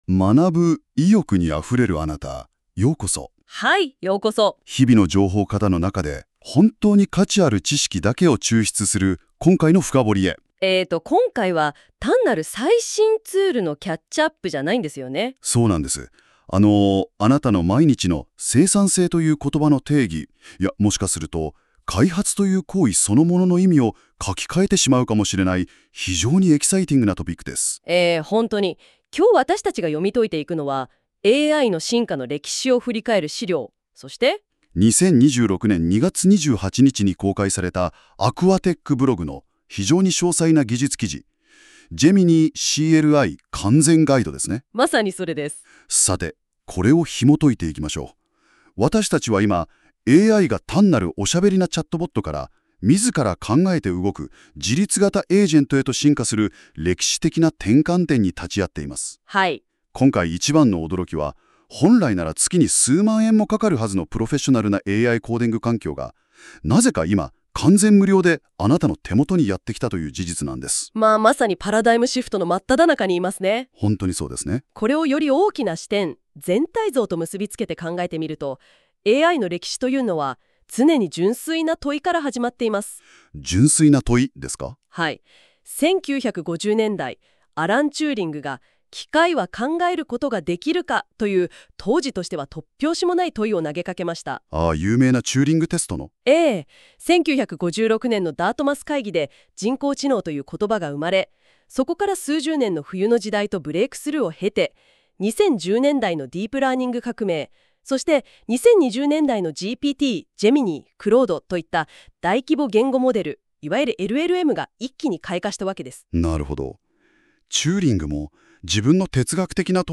本記事をGoogle NotebookLMのソースとして読み込ませたところ、19分のAIポッドキャスト、AI動画解説、57枚のフラッシュカード、マインドマップが自動生成されました。